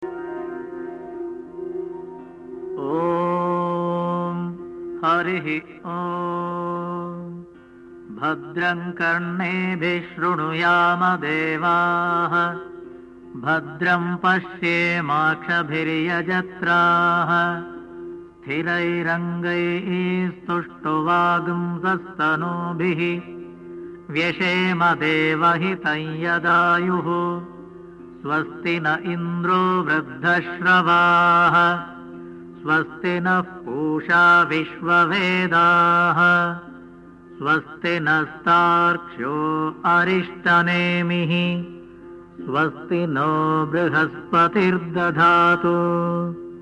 Chanting